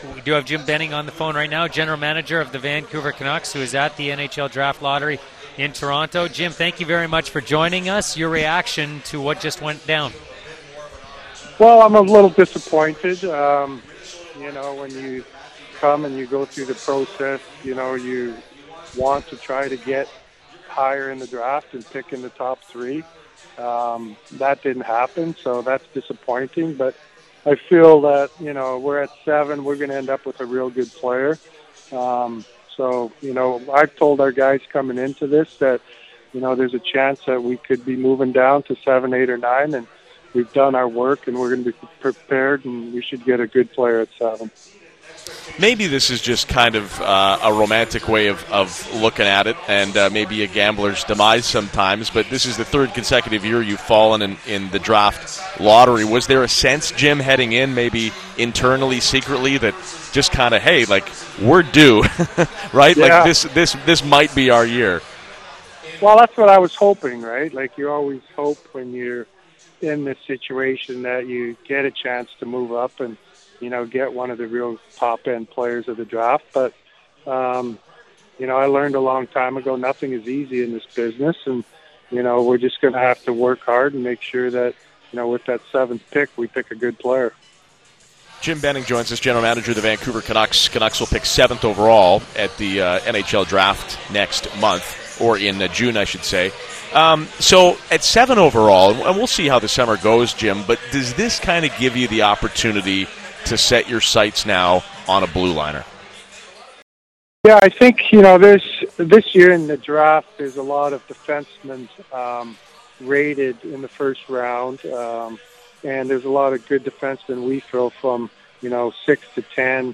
april-28-benning-intreview-v21.mp3